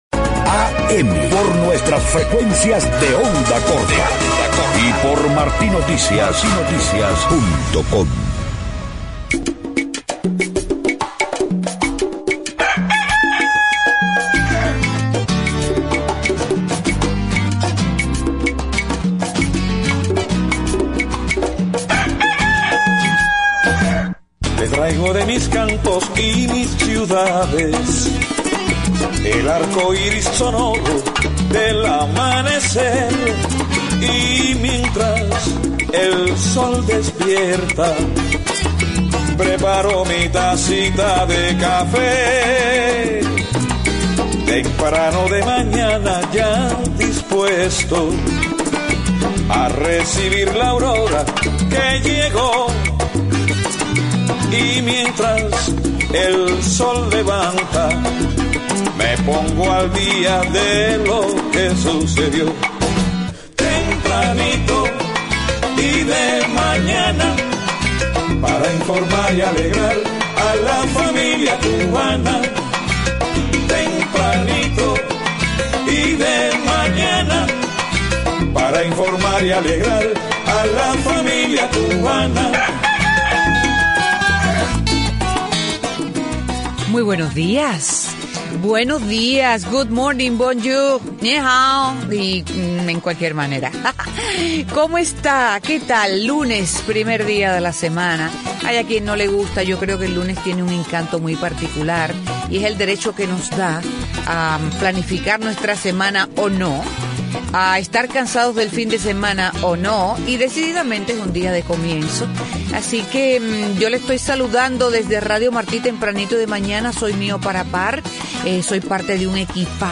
Hoy dedicamos las cuatro horas del programa a La Habana, la ciudad capital cubana, símbolo de multiples sensaciones. Un espacio replete de nostalgia, recuerdos, música y comentarios, donde los presentadores pudieron expresar sus más profundos sentimientos.